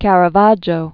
(kărə-väjō, kärä-vädjō), Michelangelo Merisi da 1573-1610.